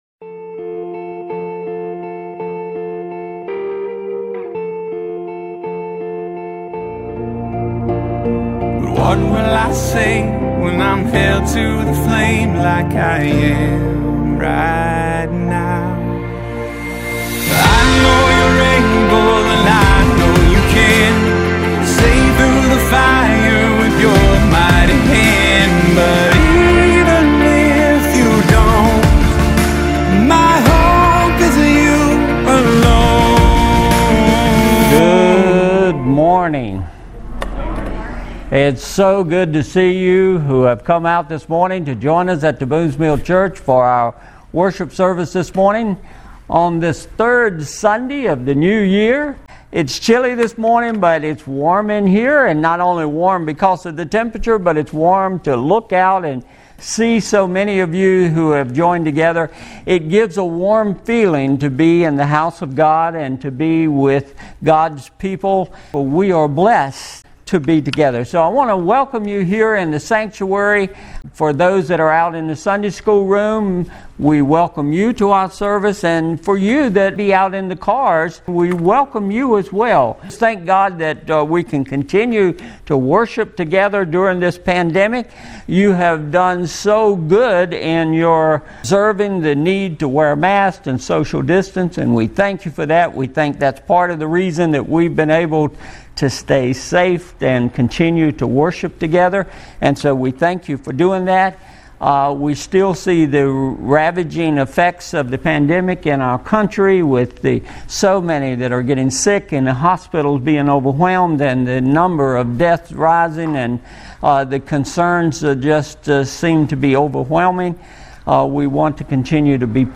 Jan 17, 2021 How to Profit from Your Problems MP3 Notes Discussion Sermons in this Series Sermon Series How to Face Our Future!